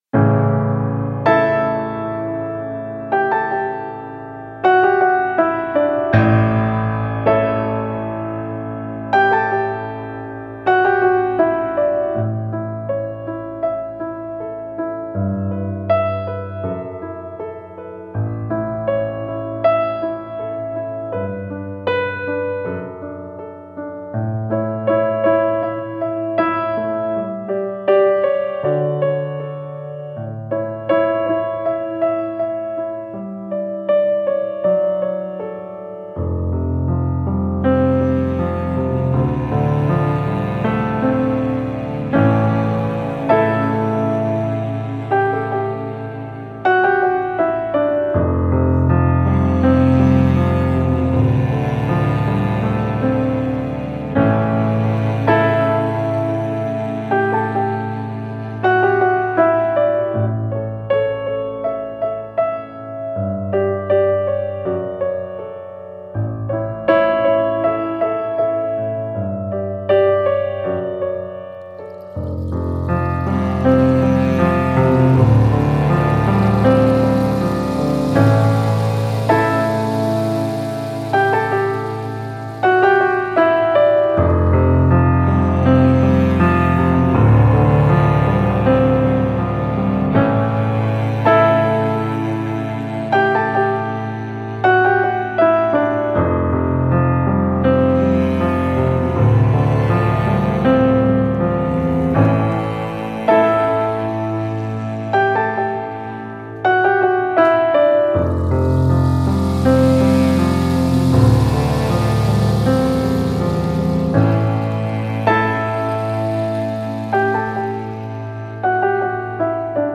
HUDOBNÉ PODKLADY